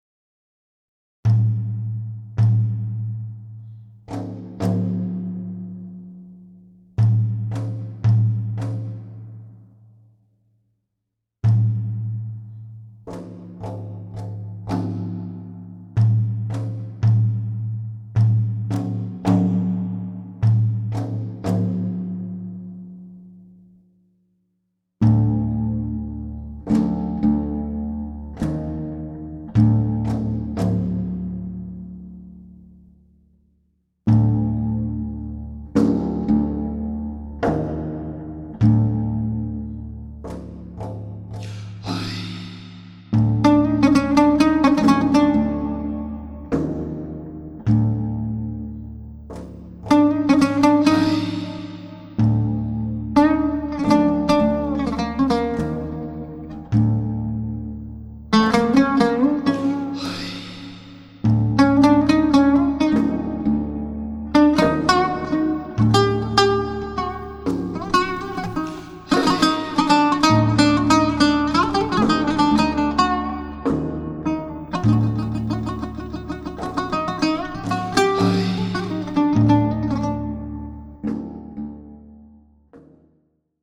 Genres: Improvisational Music on oud, Turkish Traditional.
bendir